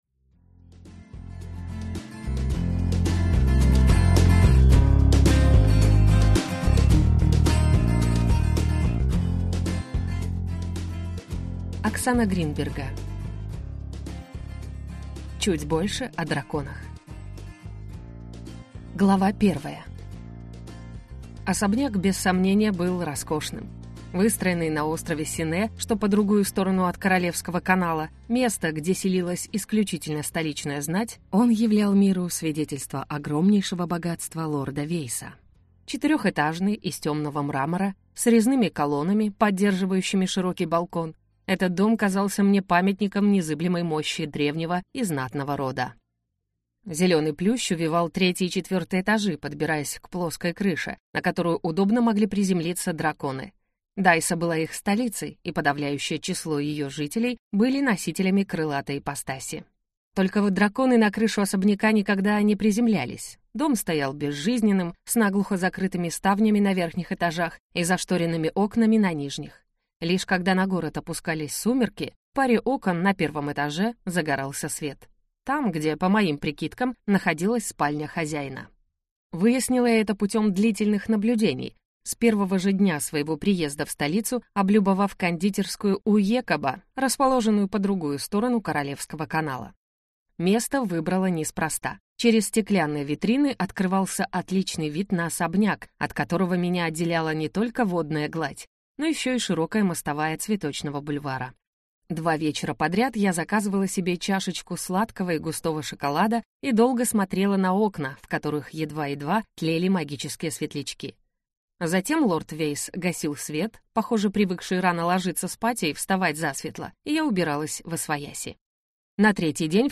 Аудиокнига Чуть больше о драконах | Библиотека аудиокниг